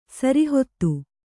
♪ sari hottu